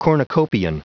Prononciation du mot cornucopian en anglais (fichier audio)
Prononciation du mot : cornucopian